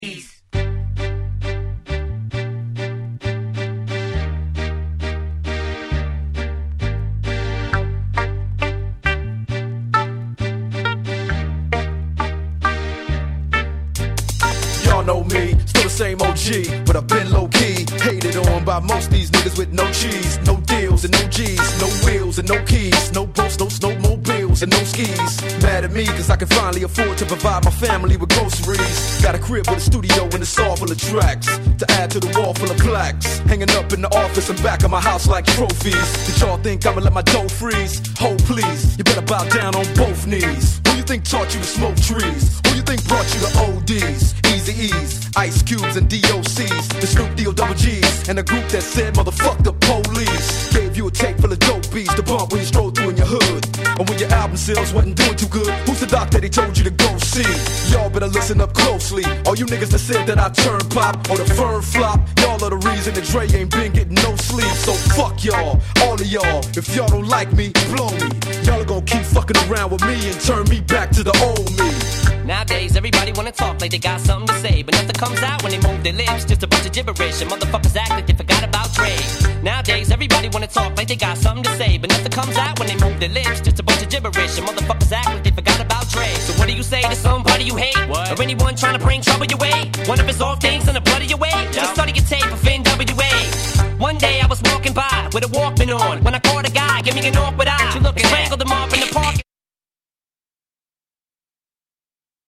G-Rap Gangsta Rap